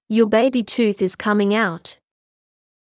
ﾕｱ ﾍﾞｲﾋﾞｰ ﾄｩｰｽ ｲｽﾞ ｶﾐﾝｸﾞ ｱｳﾄ